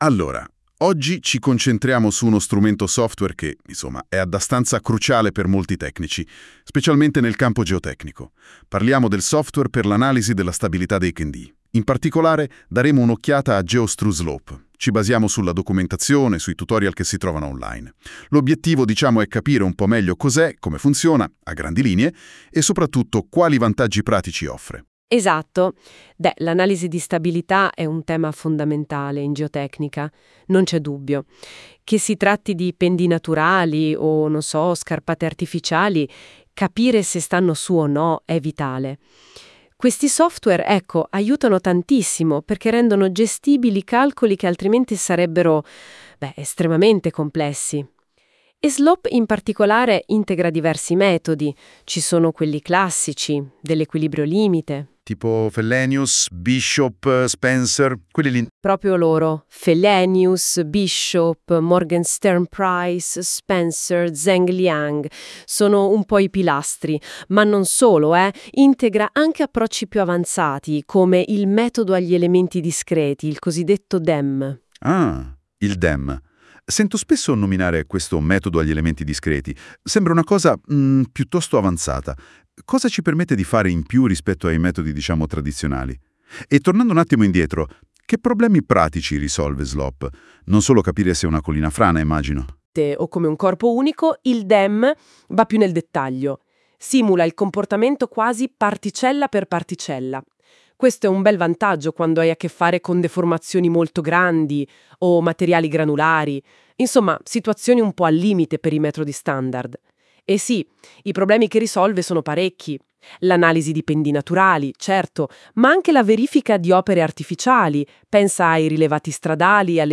Ascolta il riassunto audio generato con l’intelligenza artificiale 🤖:
Tutorial-Stabilita-Pendii-con-Slope-2.wav